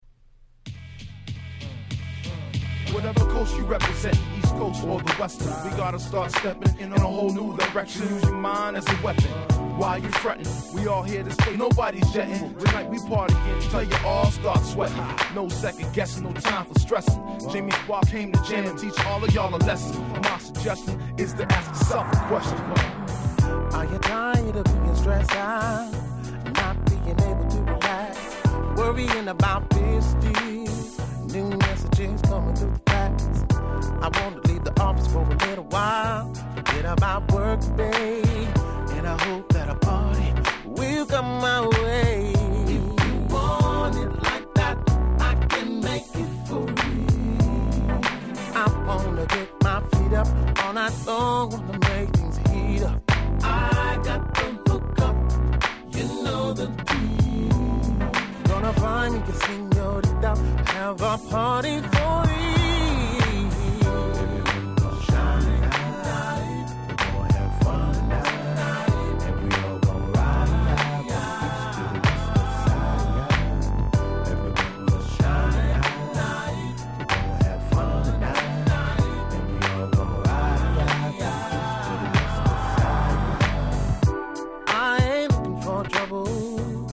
04' Nice R&B !!
Smoothだし歌唱力あるしでこれじゃ海外のマニアが欲しがるのも頷けます。